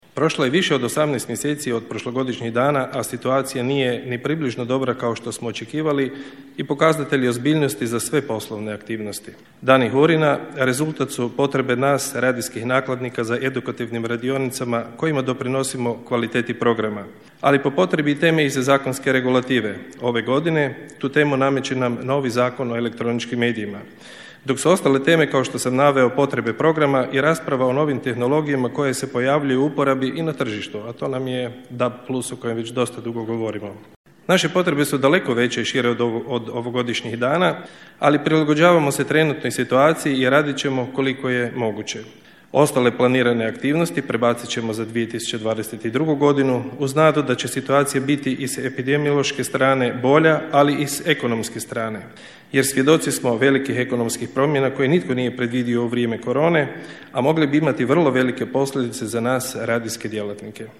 Dani HURiN-a održavaju se u Svetom Martinu na Muri u organizaciji Hrvatske udruge radijskih nakladnika, a u partnerstvu Zaklade Konrad Adenauer, HAKOM-a (Hrvatske regulatorne agencije za mrežne djelatnosti) i OIV (Odašiljača i veza).
u svom govoru na svečanom otvaranju istaknuo je: